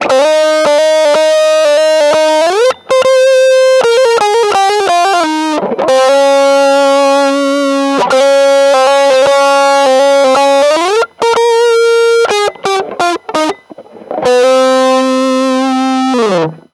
Guitar: Brian May Red Special